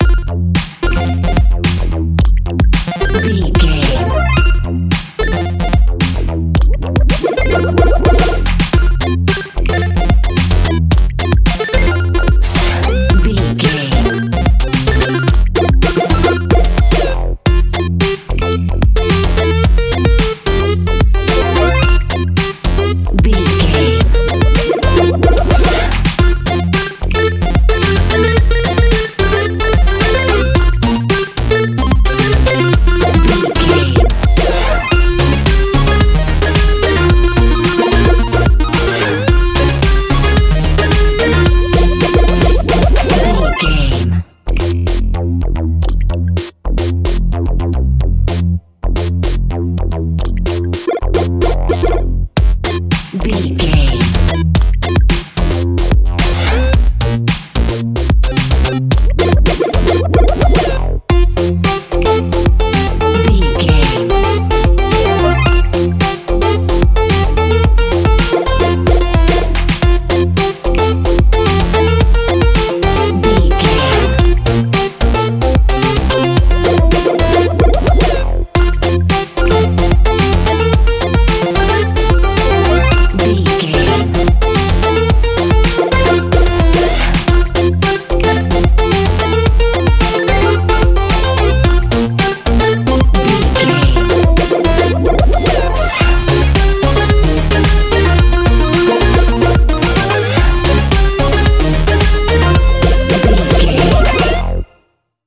This is positive and funny electronic music soundtrack
with 8 bit chiptune sounds and a punchy breakbeat
Ionian/Major
bouncy
energetic
drum machine